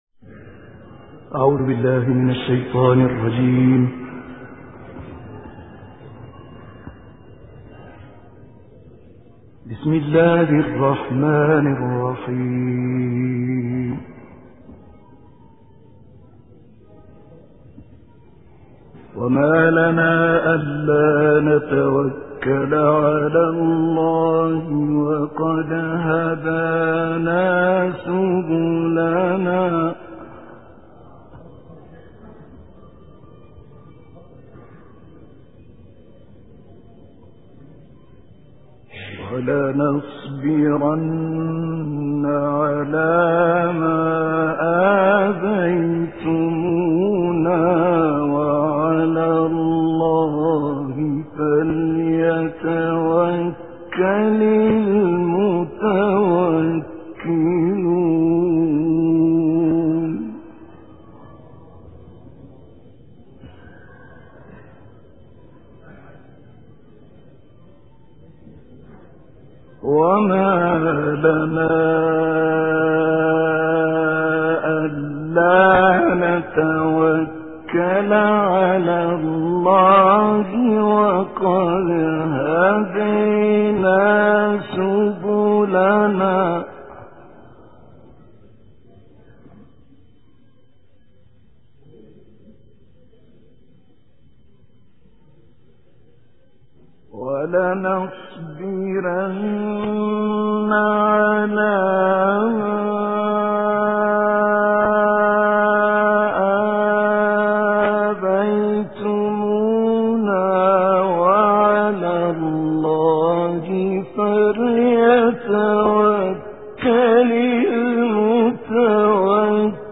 دانلود قرائت سوره ابراهیم آیات 28 تا 45 - استاد طه الفشنی
سوره-ابراهیم-آیات-28-تا-45-استاد-طه-الفشنی.mp3